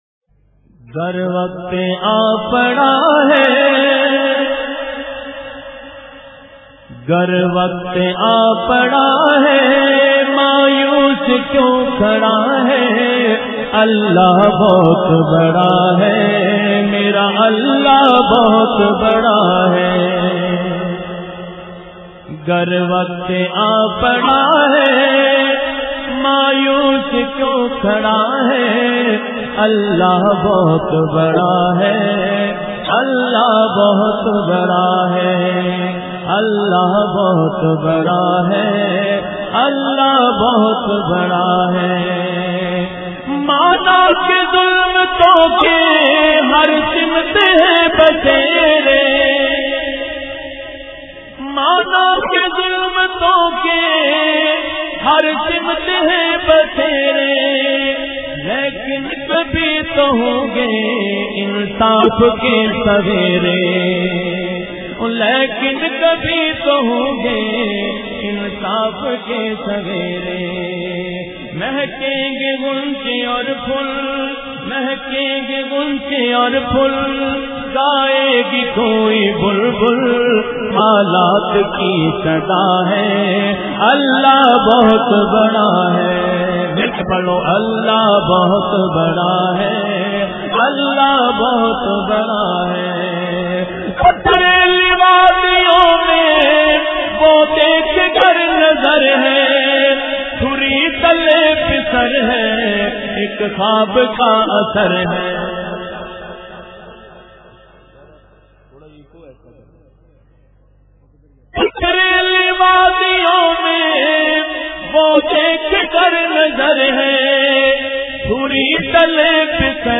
Naats
Nazmain